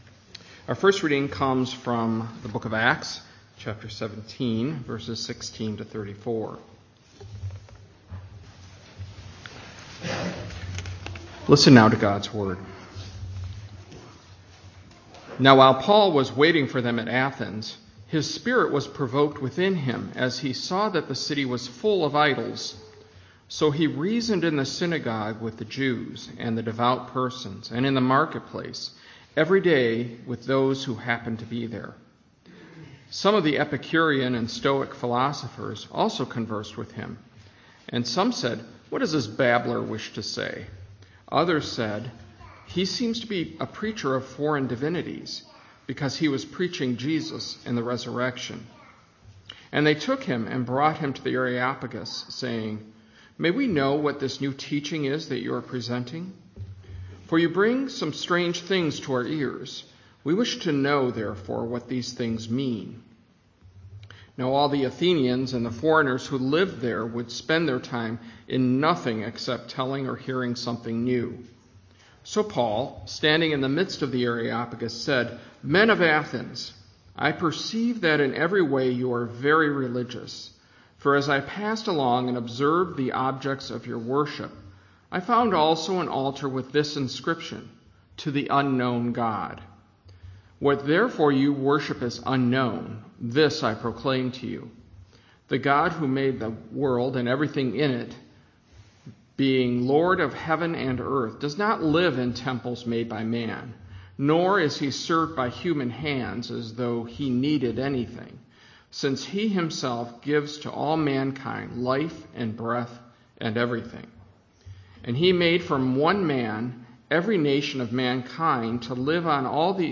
Sermons — Christ Presbyterian Church